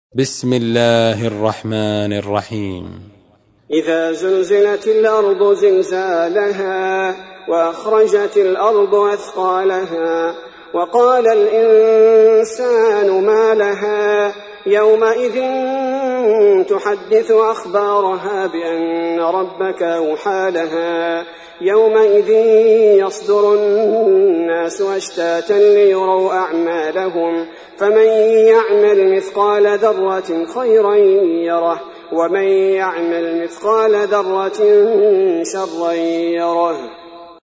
تحميل : 99. سورة الزلزلة / القارئ عبد البارئ الثبيتي / القرآن الكريم / موقع يا حسين